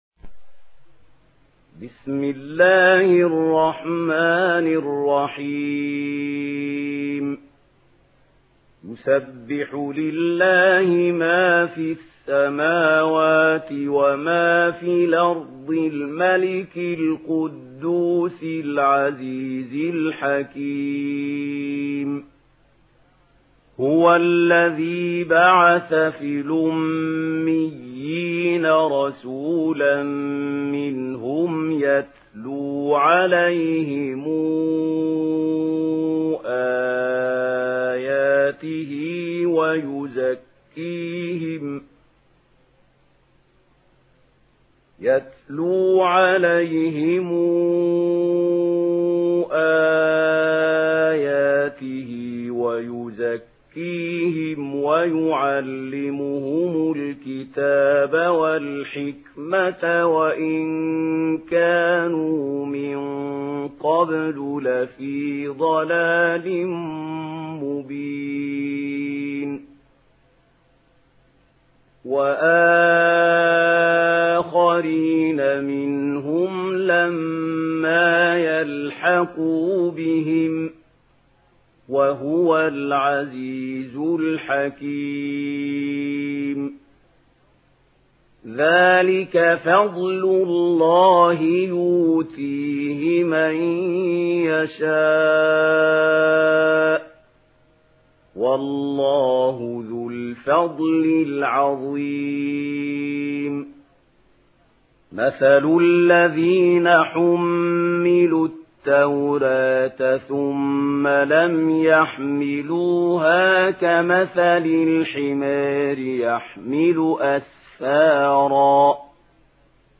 سورة الجمعة MP3 بصوت محمود خليل الحصري برواية ورش
مرتل ورش عن نافع